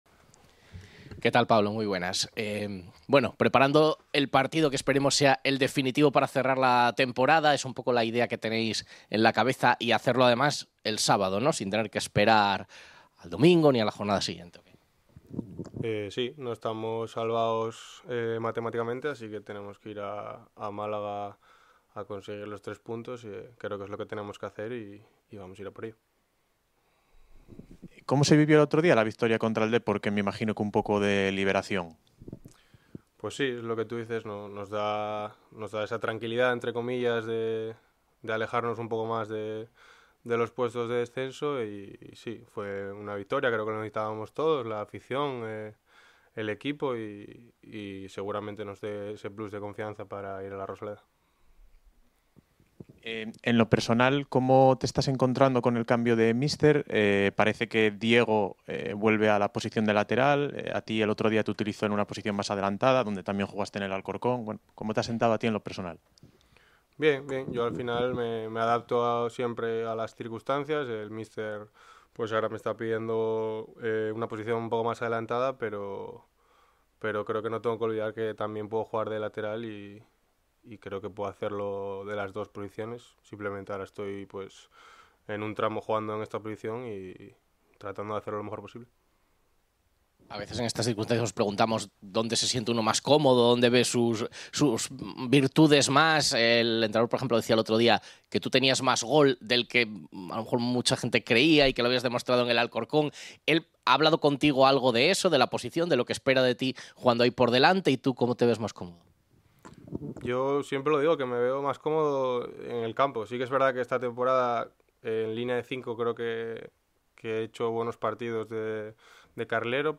habló con los medios el pasado jueves en rueda de prensa y se mostró contundente sobre esta situación